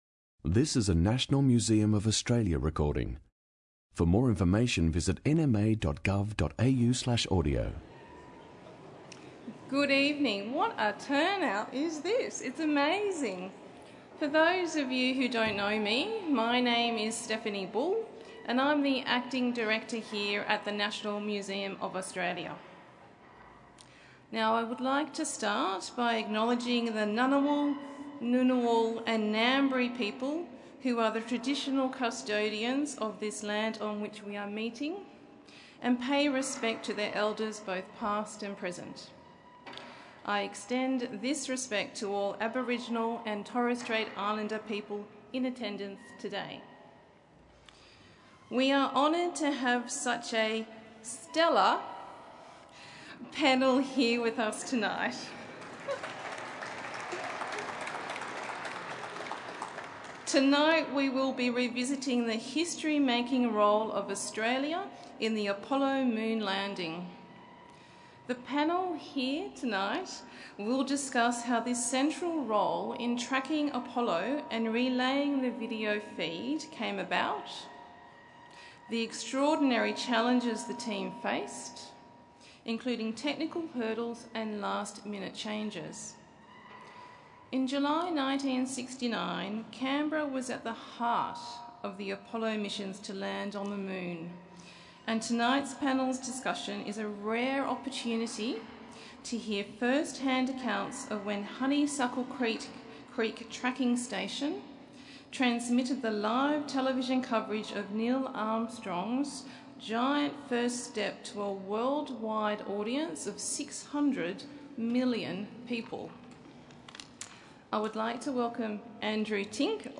Tracking Apollo: 50 Years since the Moon Landing panel discussion | National Museum of Australia